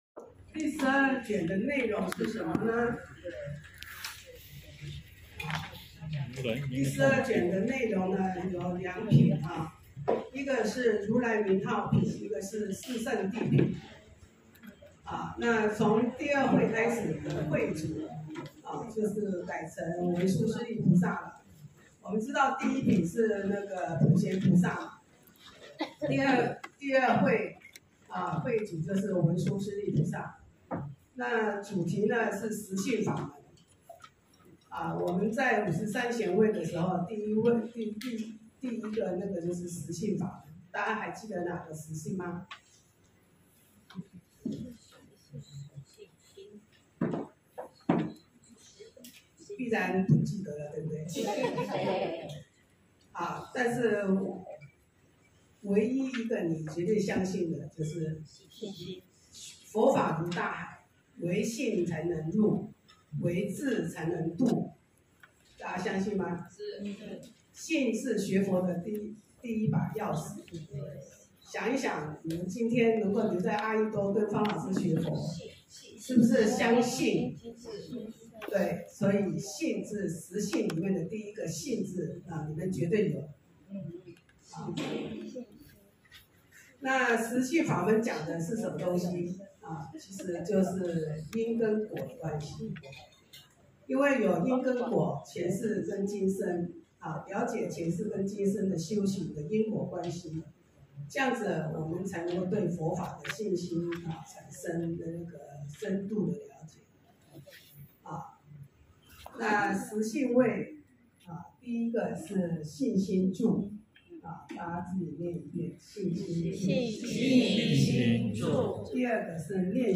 週六共修---讀誦華嚴經第12卷